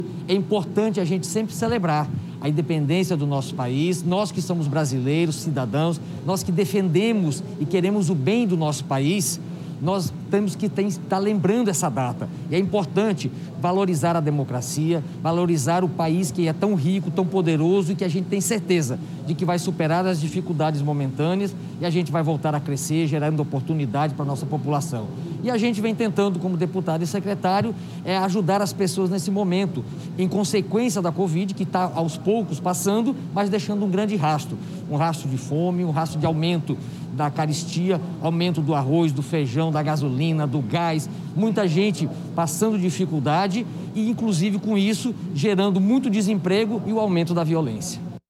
Para o Deputado Márcio Honaiser, presente à abertura dos hasteamentos e dos desfiles “é importante o momento patriótico e a valorização do país”.